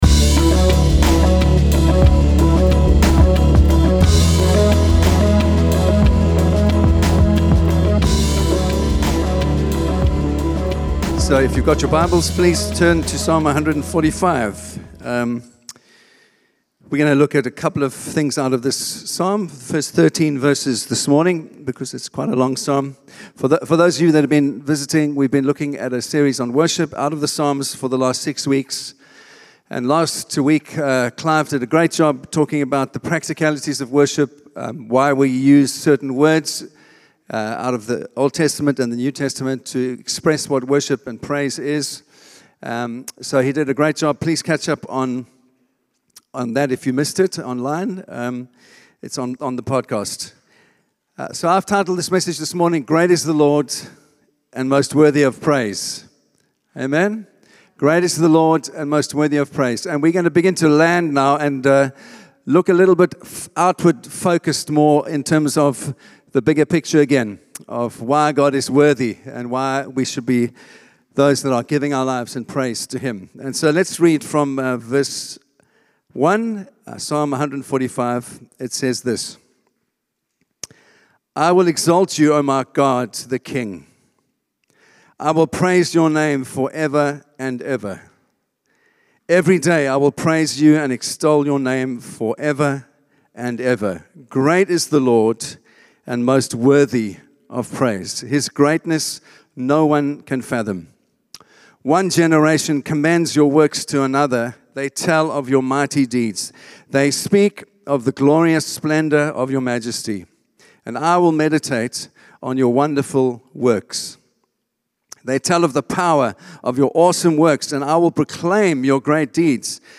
Here you have a chance to listen to the sermons at Forest Town Church.